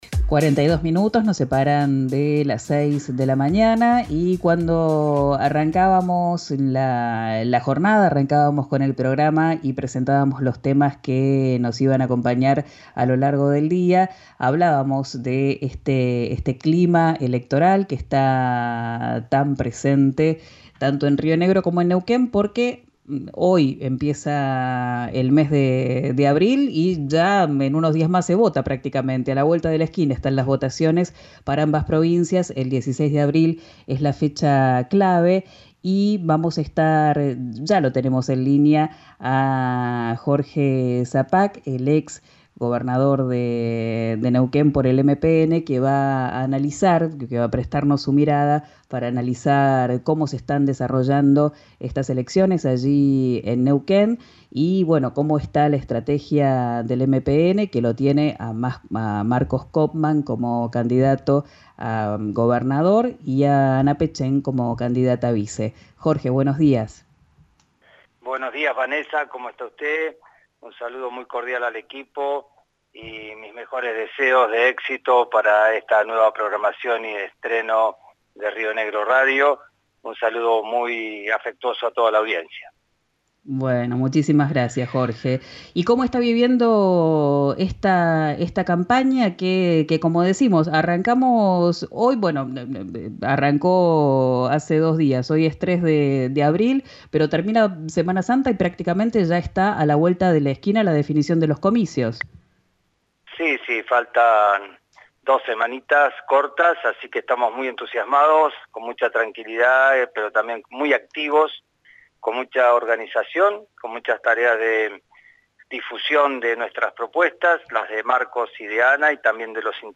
El ex gobernador de Neuquén, Jorge Sapag, habló con “Arranquemos” el programa de la primera mañana de RÍO NEGRO RADIO.